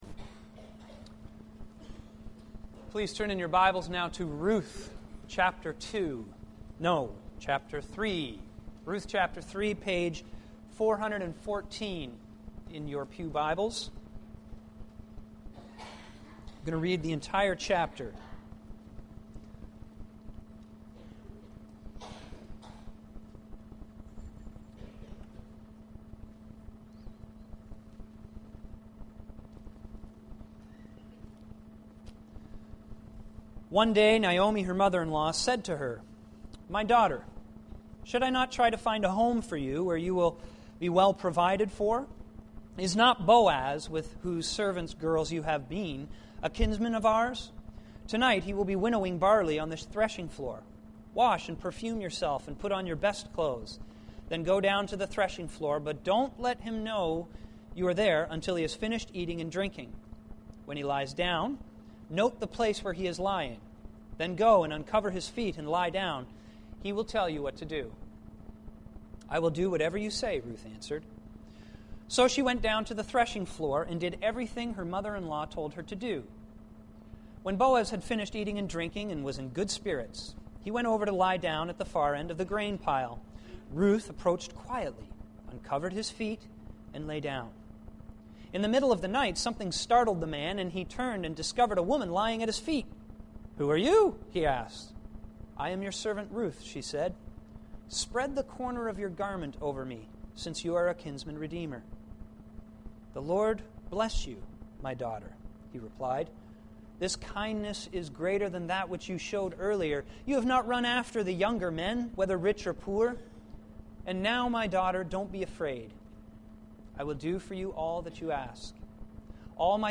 Sermon Library